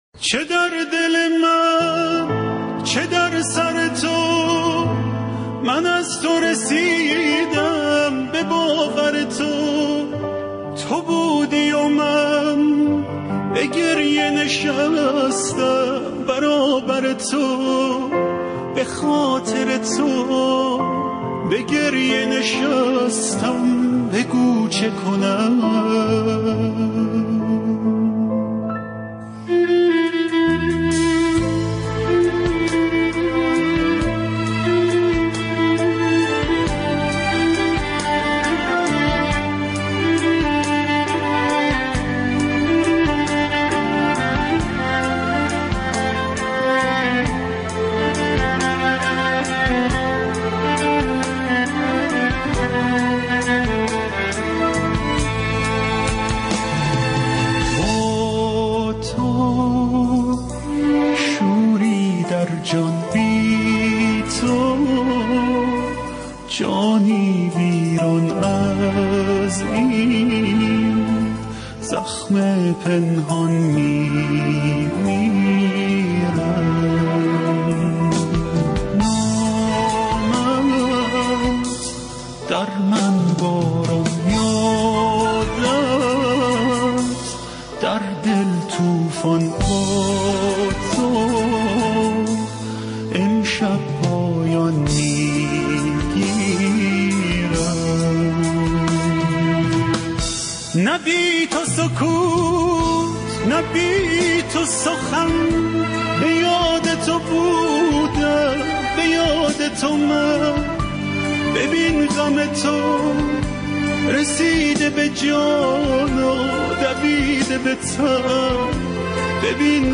تیتراژ